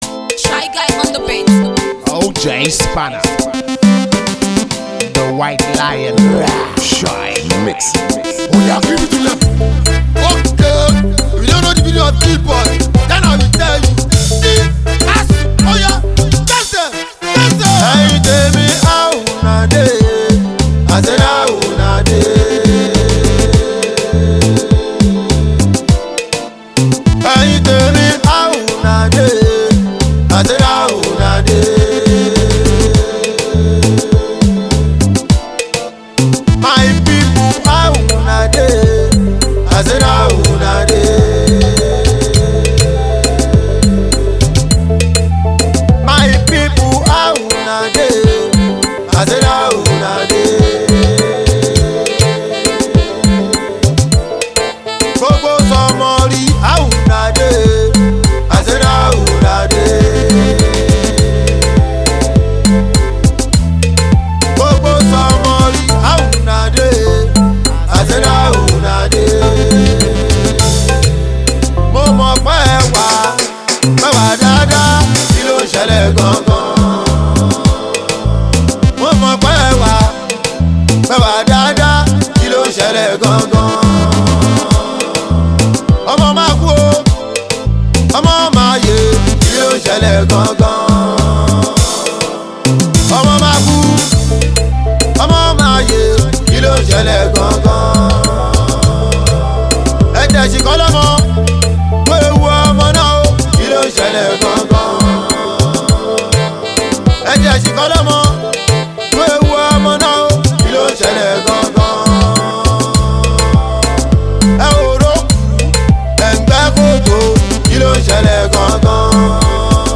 Fuji
keep on your feet dancing to its melodies